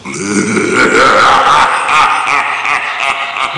Big Laughter Sound Effect
Download a high-quality big laughter sound effect.
big-laughter.mp3